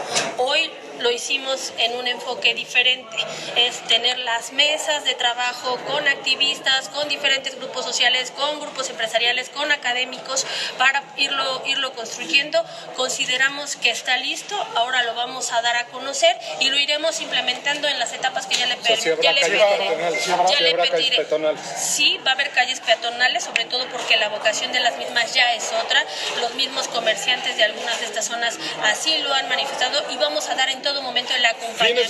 En entrevista posterior a participar en la entrega del reconocimiento al ganador de Políticas Públicas Agenda -2030 y de cuento en Palacio Municipal, Rivera Vivanco destacó que entre los beneficios que se tendrá con la nueva implementación de la cultura de la movilidad se buscará el apropiarse de espacios públicos.